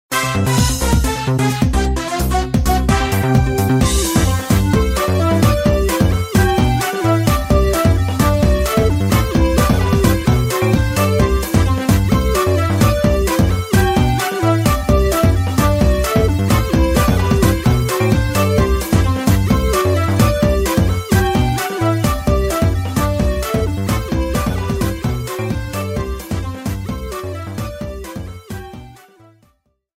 The minigame draw theme